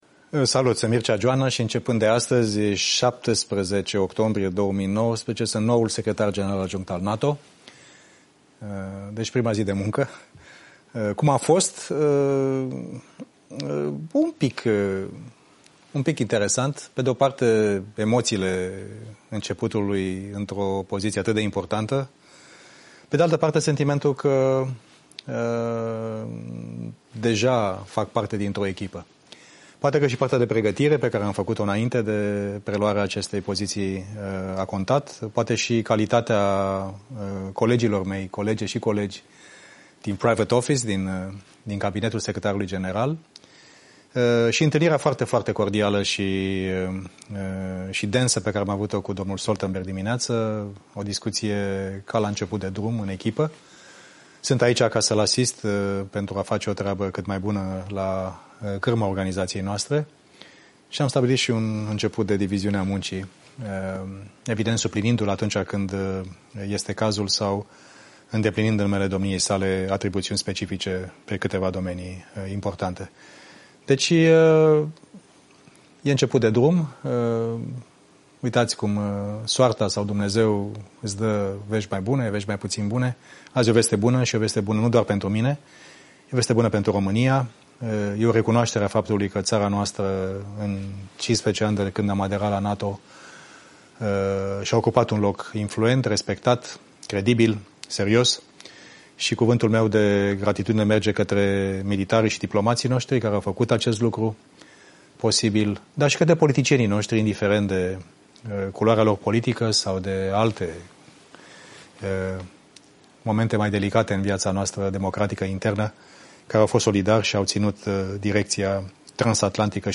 Declaratie Mircea Geoana la preluarea functiei de secretar general adjunct al NATO